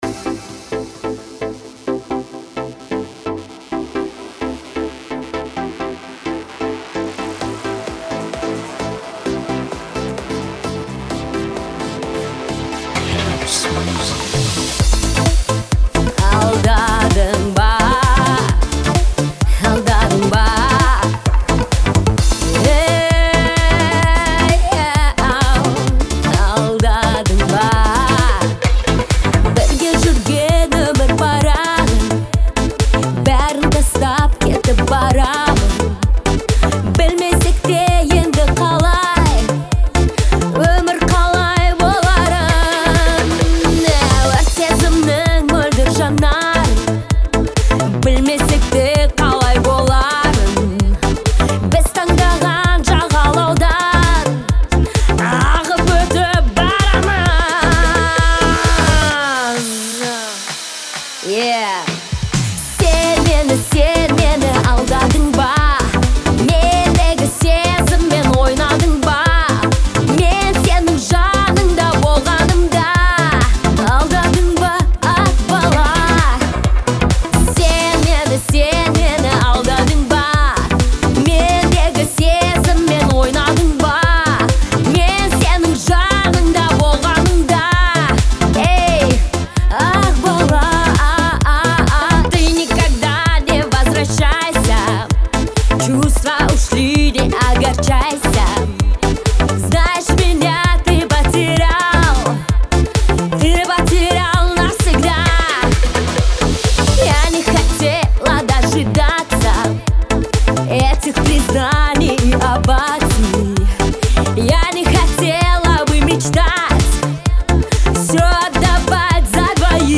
это эмоциональный трек в жанре поп
Звучание отличается мелодичностью и выразительным вокалом